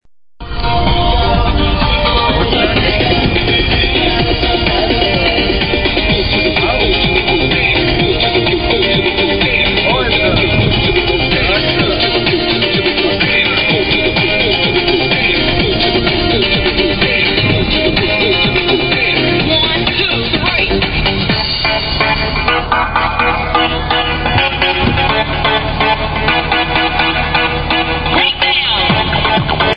i heard this song on the radio the other day, recorded it via my cell phone builtin recording fuction.